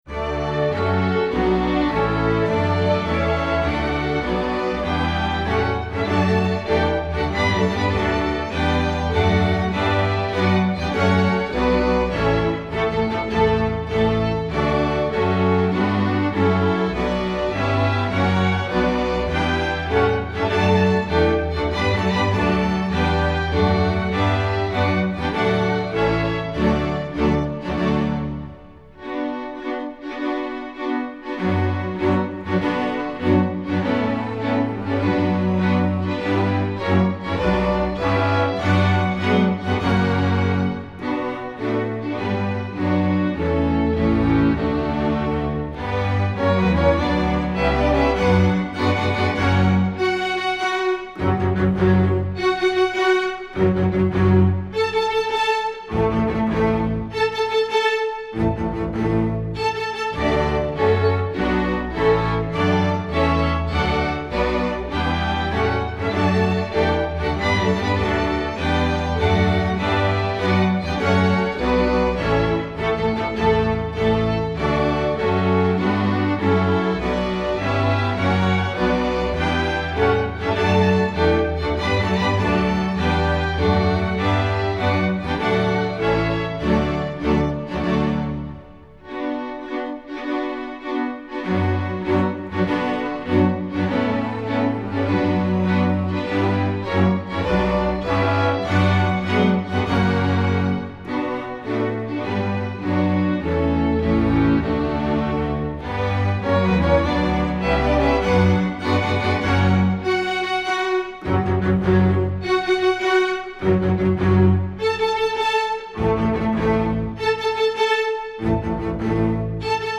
Instrumentation: string orchestra
instructional